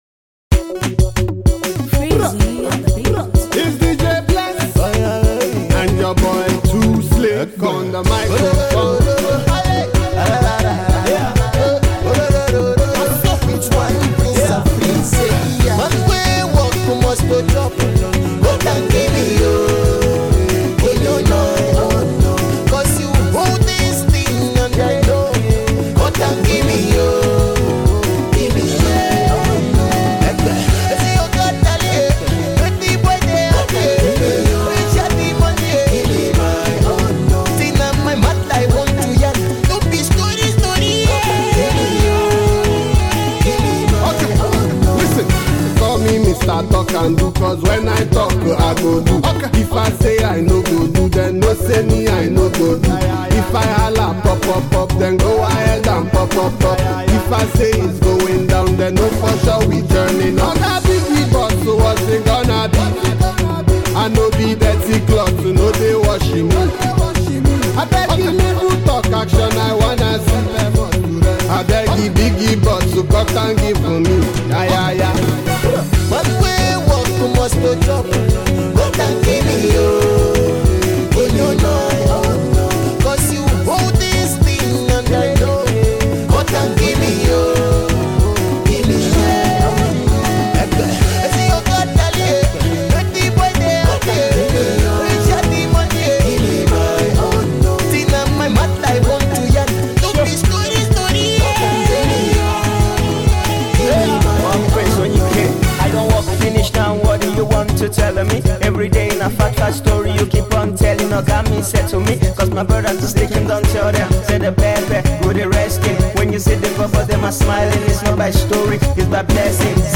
Home Alternative Pop Audio